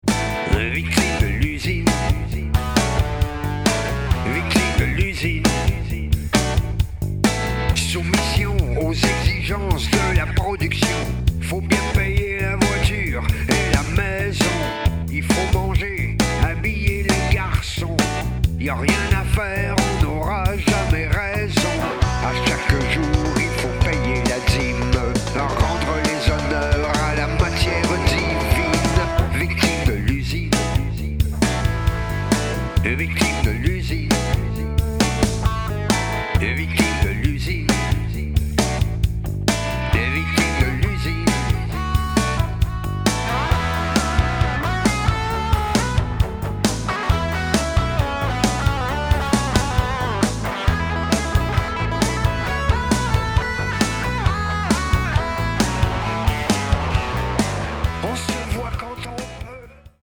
chante et  parle
guitariste
album électrique